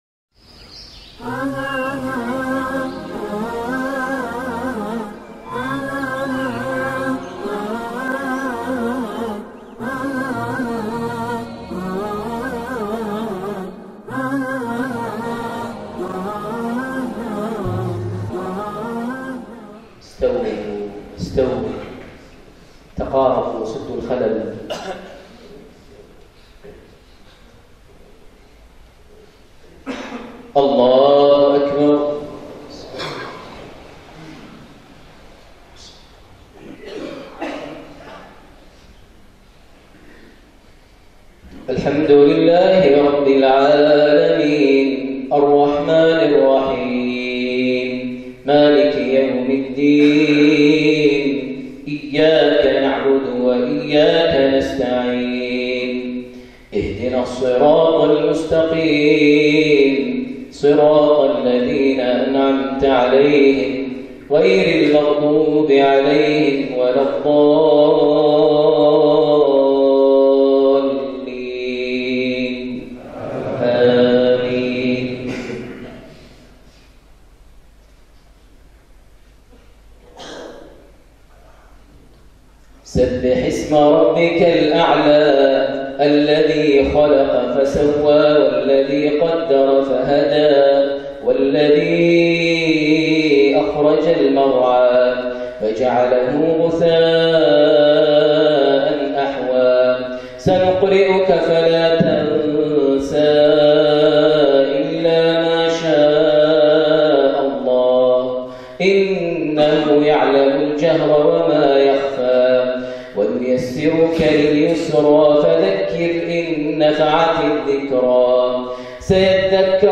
من قيرغيزستان | صلاة الجمعة لسورتي الأعلى - الغاشية عام ١٤٣٥هـ > زيارة الشيخ ماهر المعيقلي لدولة قيرغيزستان 1435هـ > المزيد - تلاوات ماهر المعيقلي